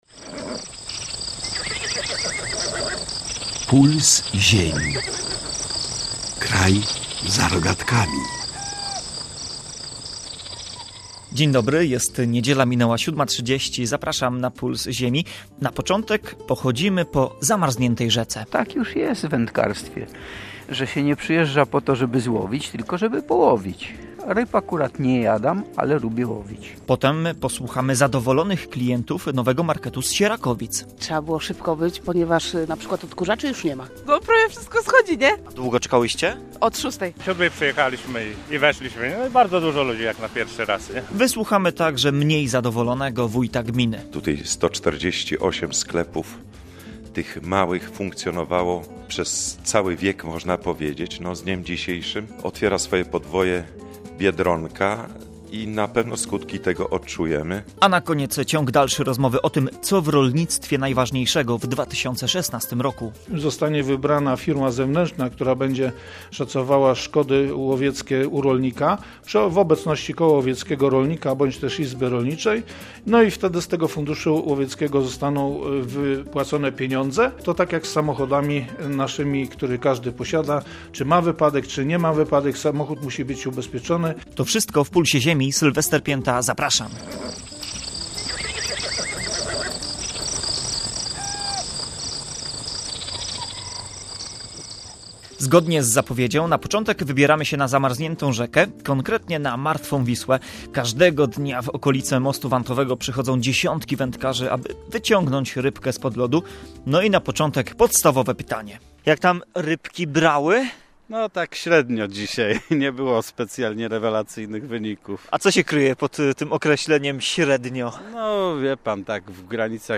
Każdego dnia w okolice Mostu Wantowego w Gdańsku przychodzą dziesiątki wędkarzy, aby wyciągnąć rybkę spod lodu. Nasz reporter na lód odważnie wszedł i zapytał „I jak? Rybka bierze?”.